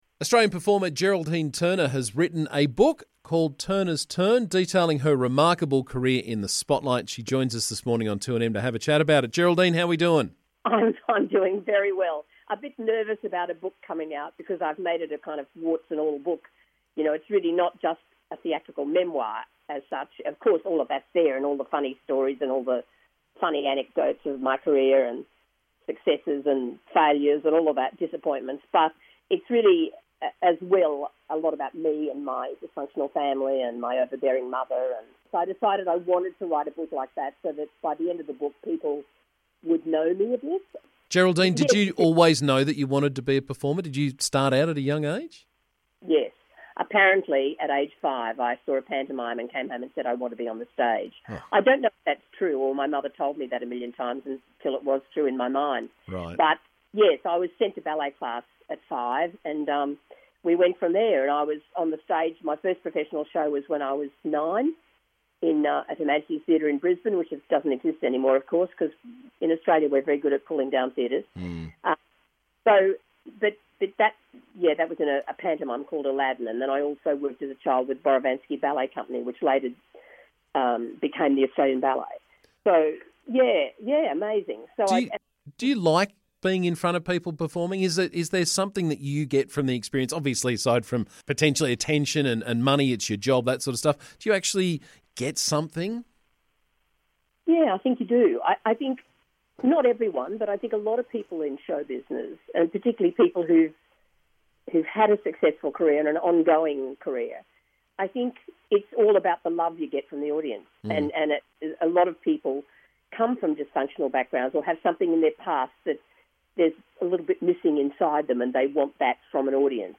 Australian performer and actress, Geraldine Turner, has penned a tell-all book about her life and career in the entertainment industry and she was on the show to tell us all more this morning.